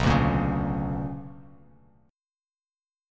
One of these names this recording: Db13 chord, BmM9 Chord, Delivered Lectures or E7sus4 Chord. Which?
BmM9 Chord